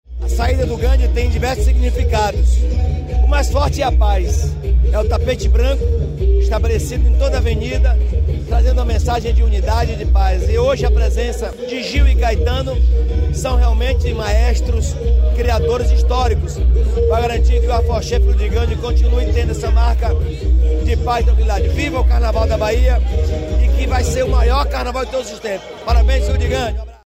🎙 Jerônimo Rodrigues prestigia saída do Gandhy neste domingo
Caracterizado como manda a tradição, o governador Jerônimo Rodrigues prestigiou o primeiro dia da saída dos Afoxés Filhos de Gandhy, neste domingo (11), na sede da associação, no Pelourinho, em Salvador.